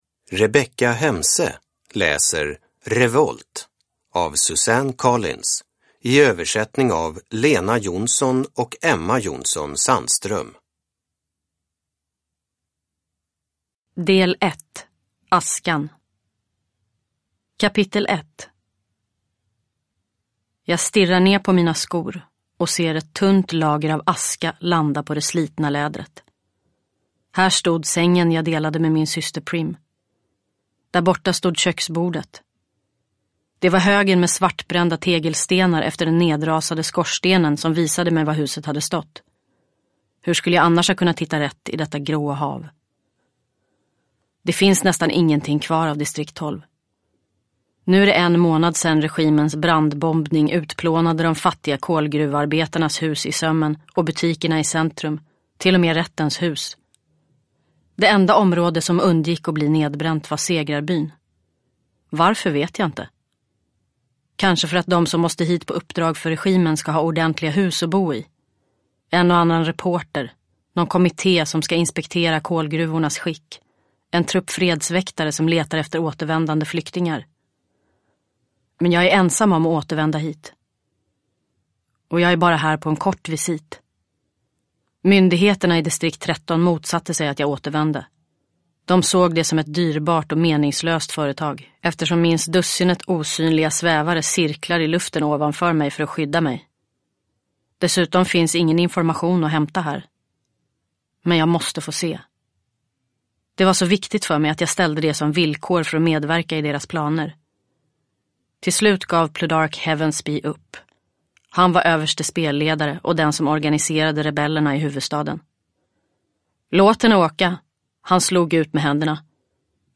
Revolt (ljudbok) av Suzanne Collins